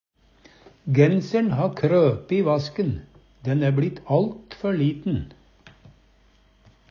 krope - Numedalsmål (en-US)